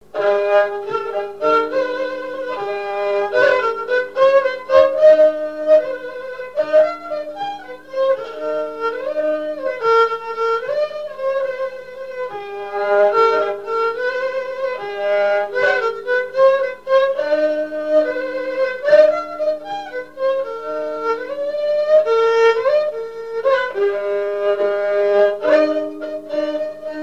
Mémoires et Patrimoines vivants - RaddO est une base de données d'archives iconographiques et sonores.
danse : valse
valse anglaise
Genre strophique
Pièce musicale inédite